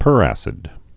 (pûrăsĭd)